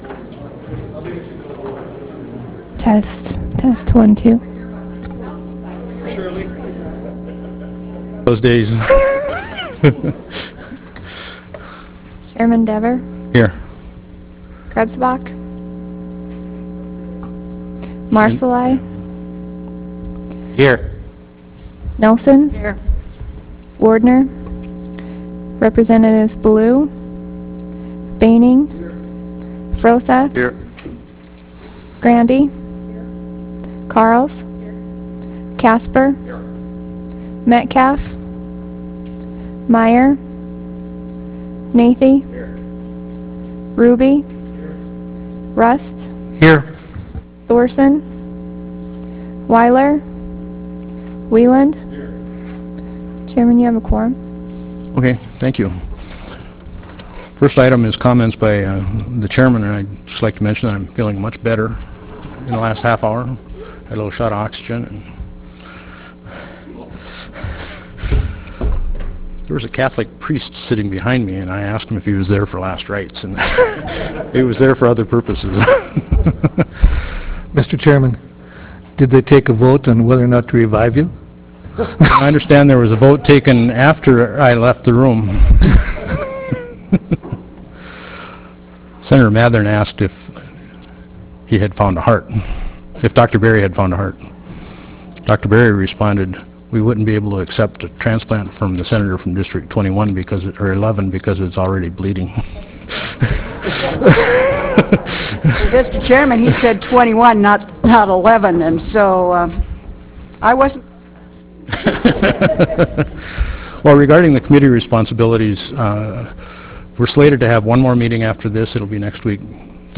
Brynhild Haugland Room State Capitol Bismarck, ND United States